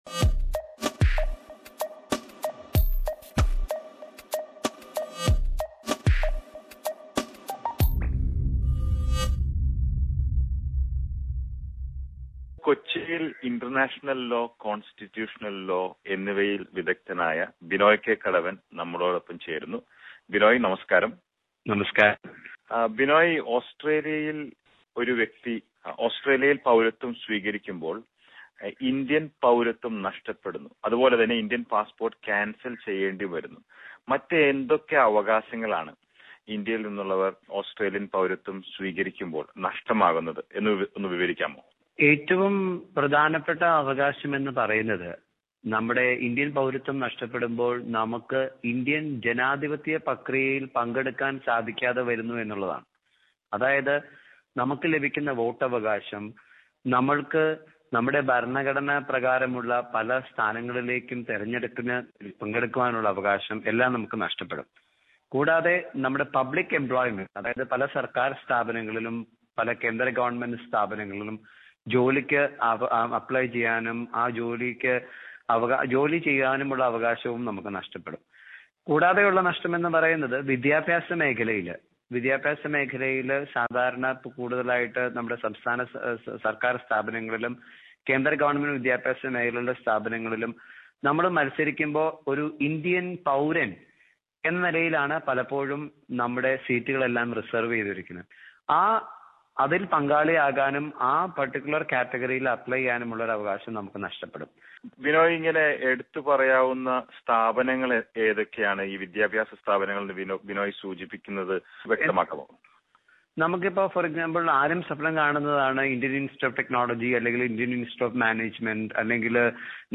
But, do you know what are the changes in your rights and responsibilities in India, when you give up Indian citizenship. Listen to the re-broadcast of this interview.